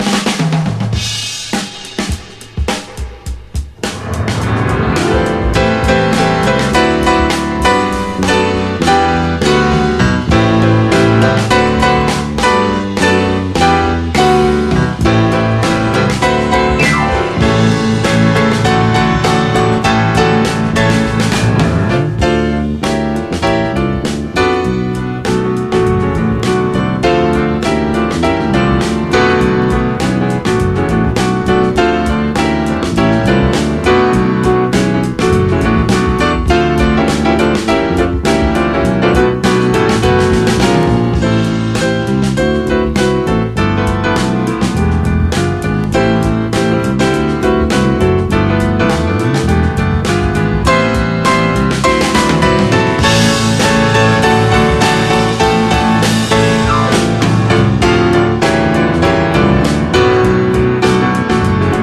JAZZ / DANCEFLOOR / JAZZ FUNK / BRAZILIAN JAZZ / RARE GROOVE
モーダルなナンバーも収録しています。